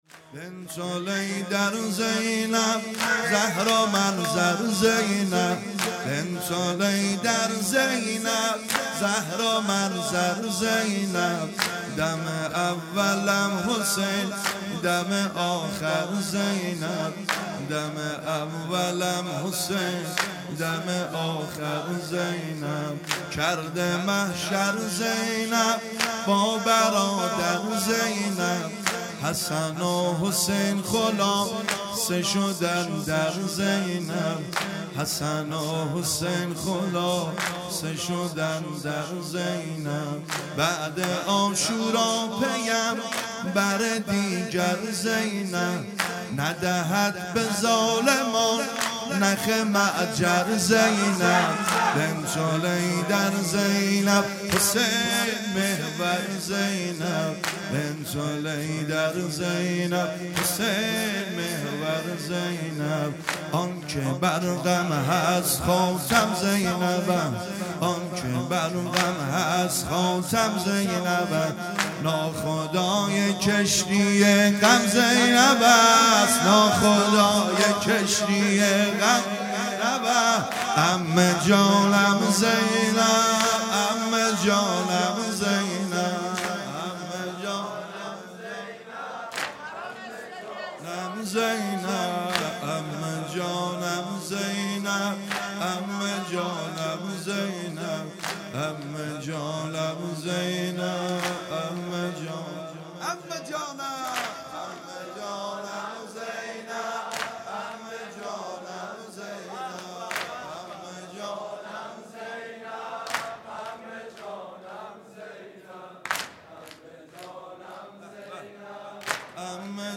هیئت دانشجویی فاطمیون دانشگاه یزد
بنت الحیدر زینب|شب اول دهه سوم محرم ۹۷